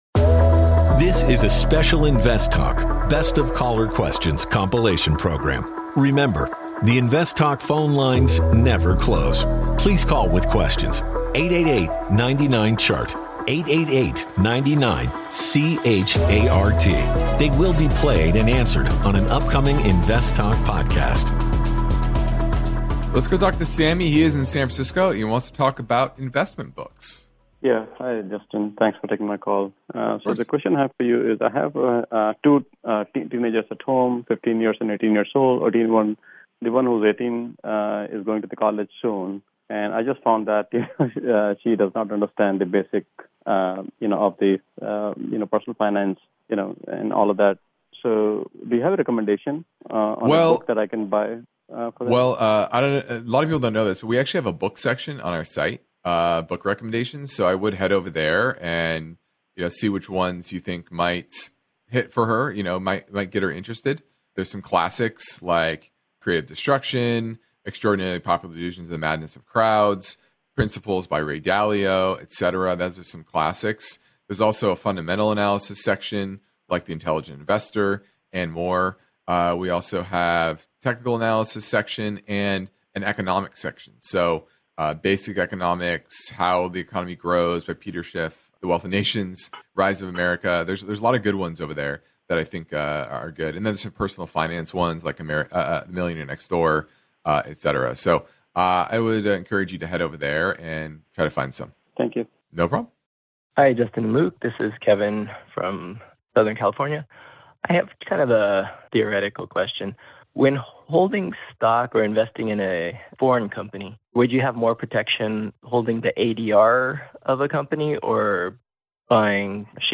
Best of Caller Questions - Labor Day Edition